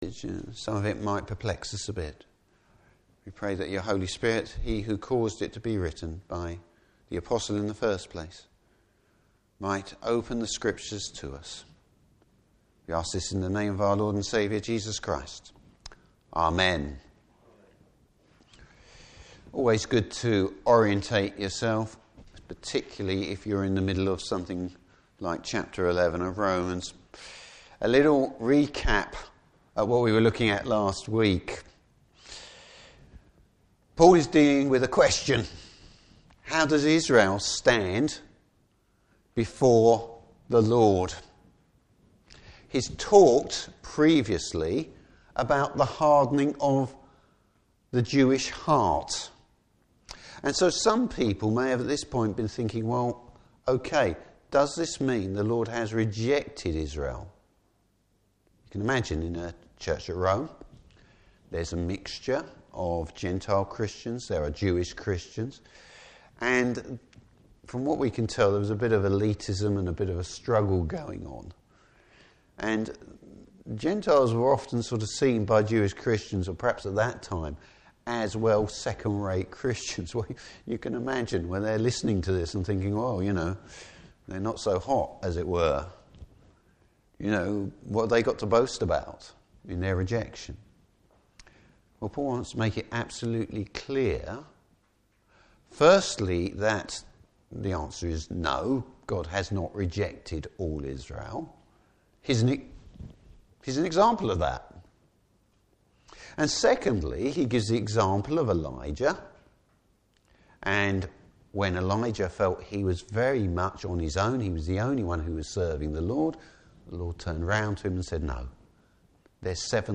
Service Type: Morning Service What does Paul mean when he writes ‘all Israel will be saved’?